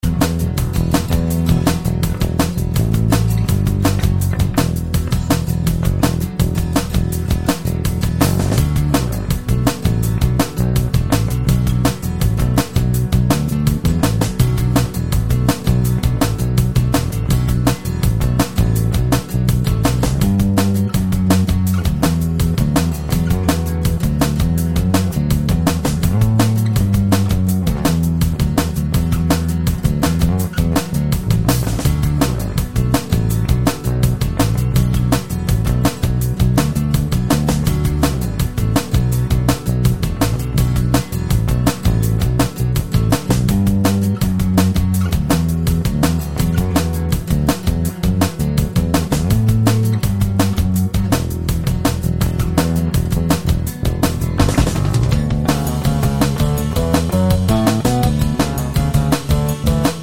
no Backing Vocals Indie / Alternative 4:04 Buy £1.50